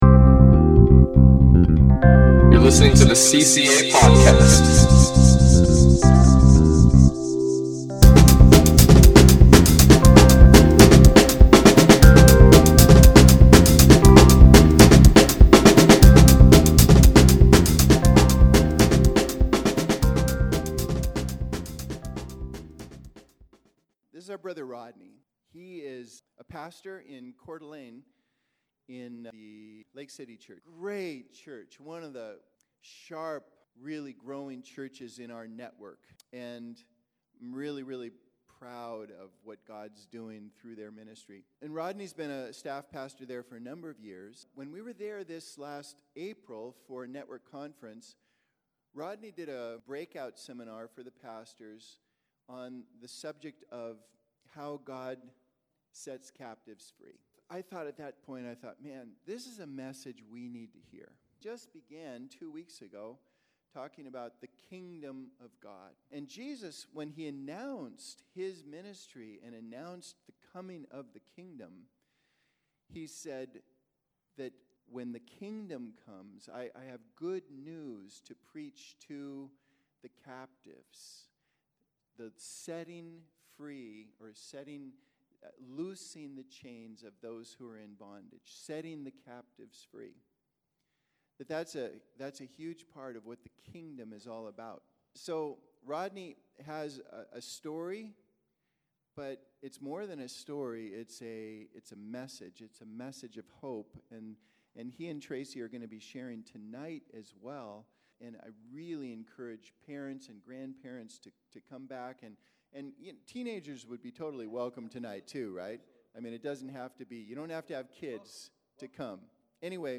Message - Calvary Christian Assembly